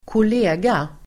Uttal: [²kol'e:ga]